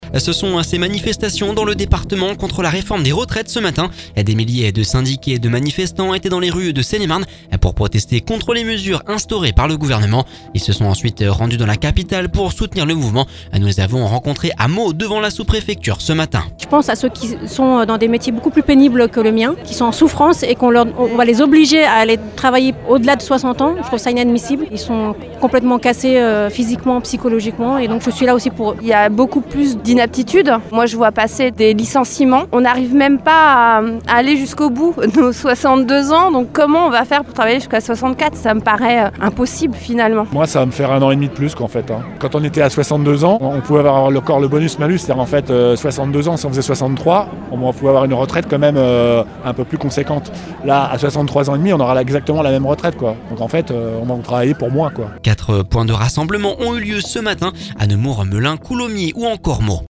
Nous les avons rencontrés à Meaux devant la Sous-Préfecture…